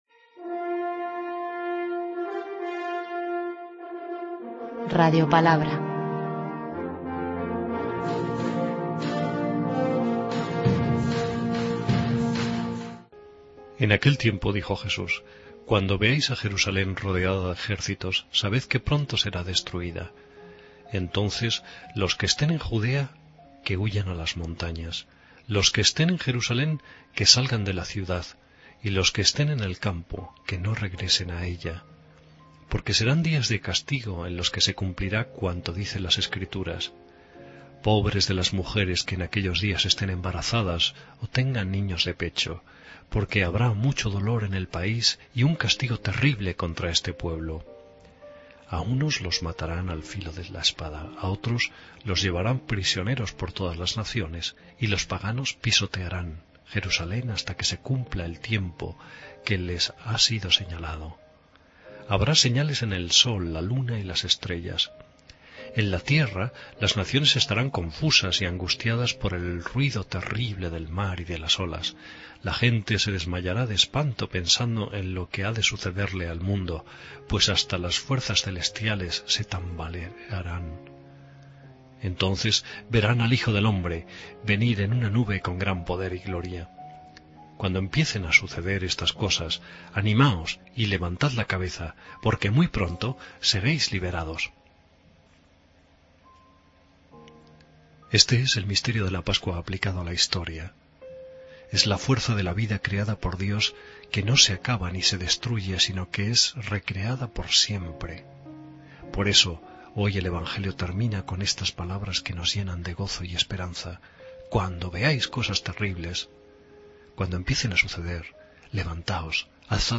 Lectura del santo evangelio según san Lucas 21,20-28: